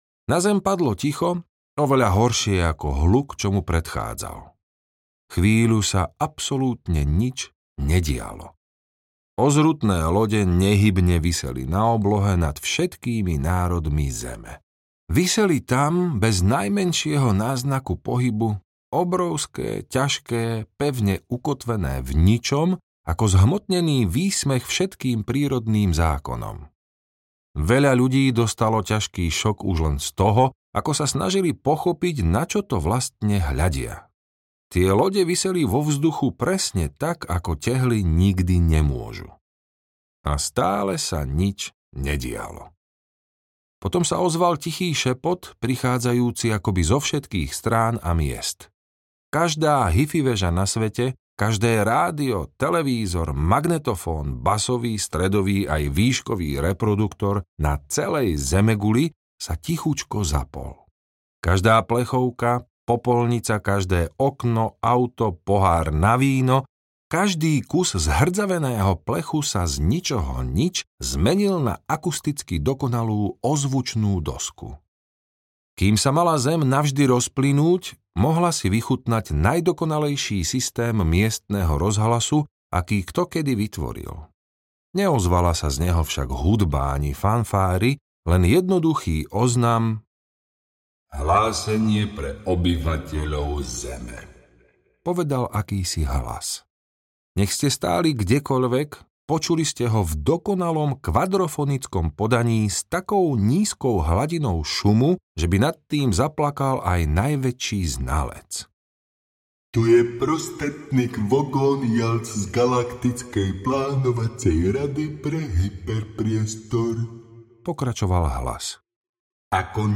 Stopárov sprievodca galaxiou audiokniha
Ukázka z knihy